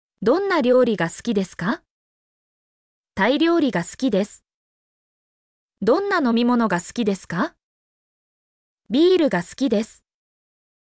mp3-output-ttsfreedotcom-2_oK2W1n0S.mp3